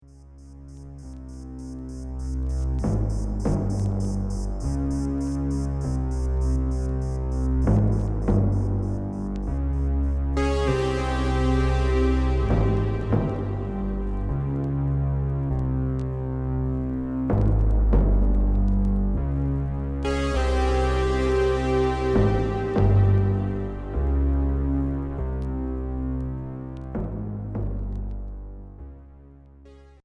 Minimal synth Unique 45t retour à l'accueil